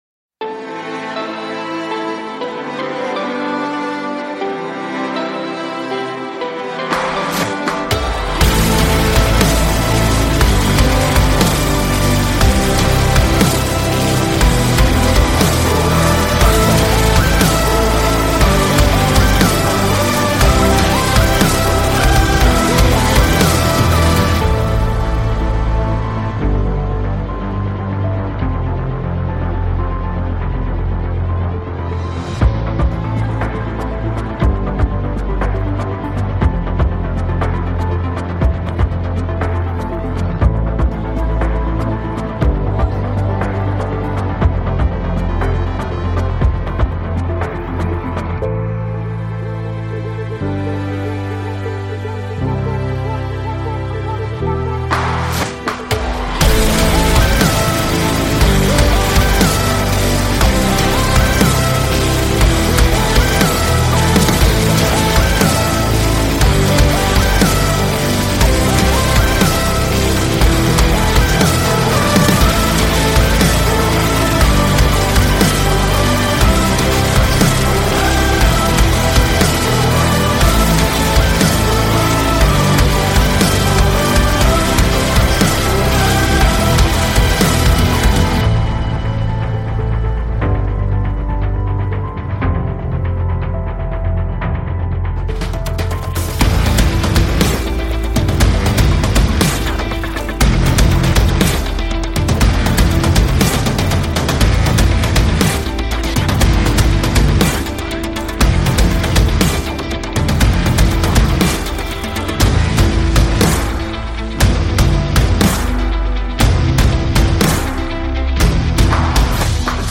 Жанр: Metal